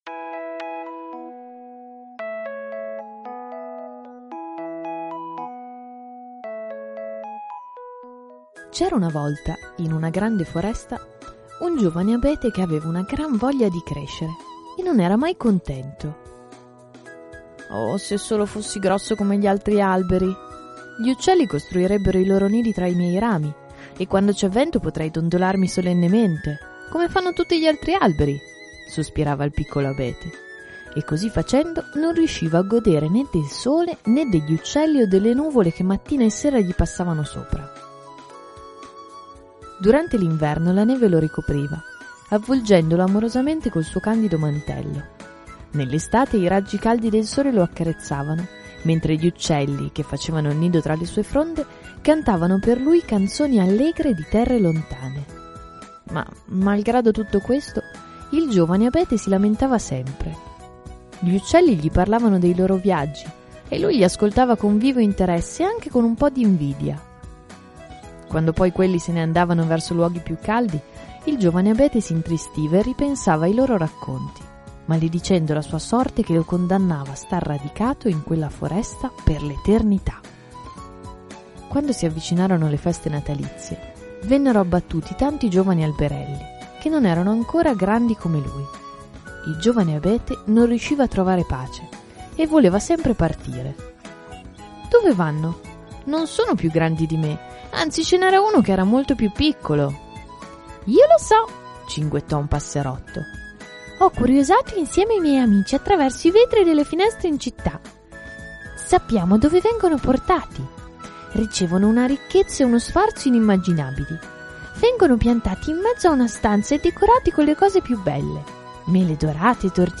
Recitato